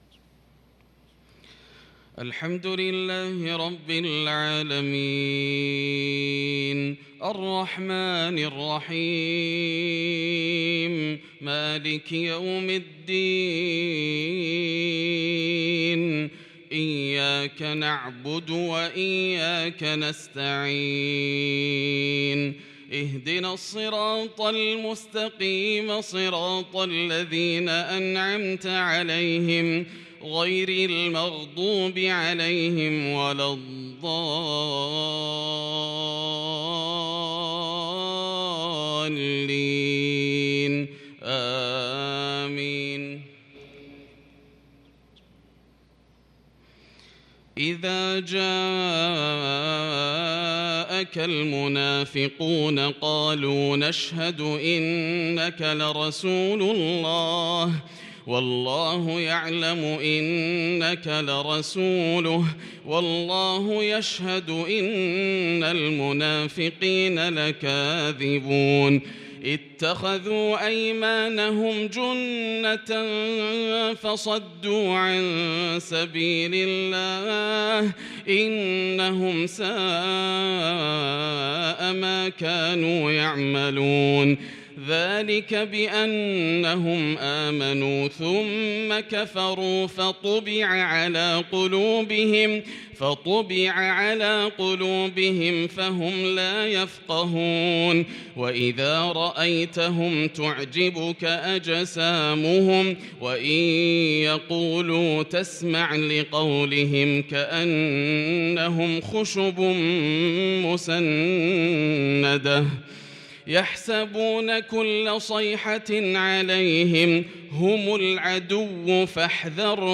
صلاة المغرب للقارئ ياسر الدوسري 23 محرم 1443 هـ
تِلَاوَات الْحَرَمَيْن .